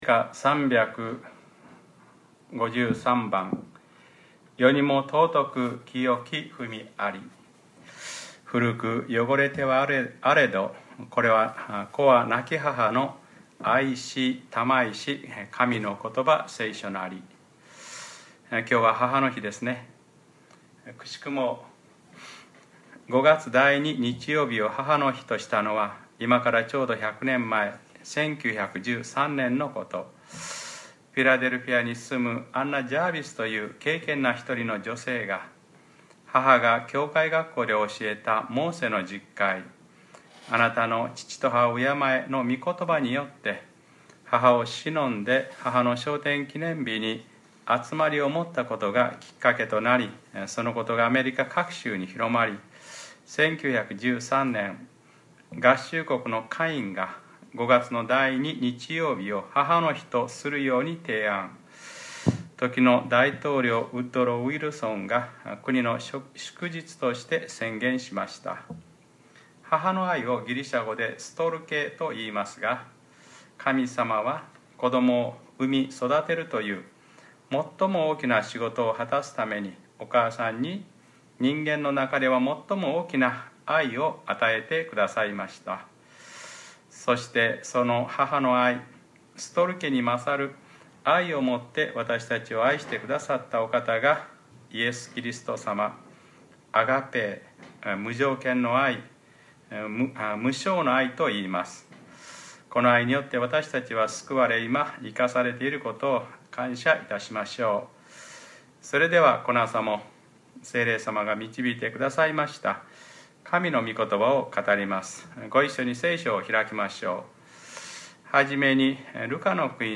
2013年5月12日(日）礼拝説教 『ルカ-20 ただ自分の敵を愛しなさい』